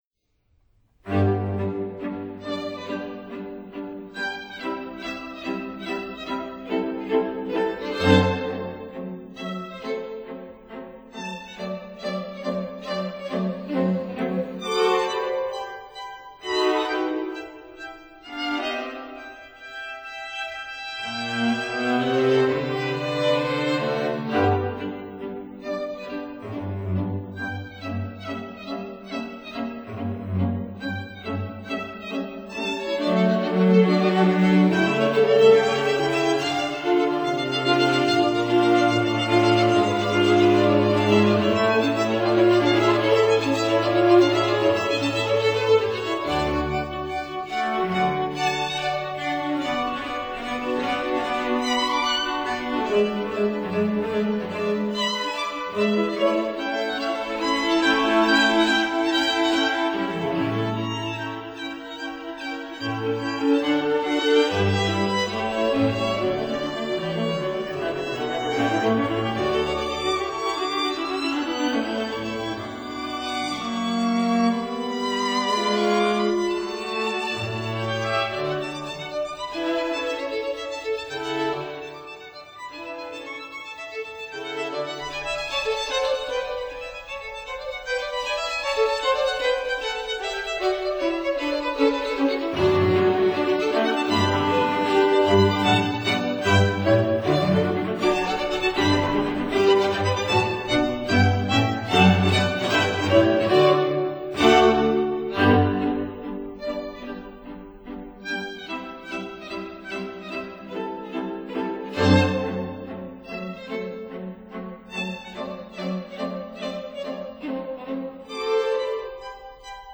String Quartets by:
(Period Instruments)